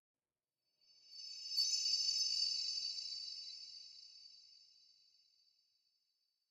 Minecraft Version Minecraft Version snapshot Latest Release | Latest Snapshot snapshot / assets / minecraft / sounds / ambient / nether / crimson_forest / shine2.ogg Compare With Compare With Latest Release | Latest Snapshot